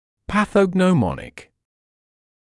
[ˌpaθəgnəu’mɒnɪk][ˌпэсэгноу’моник]патогномоничный, характерный для данной болезни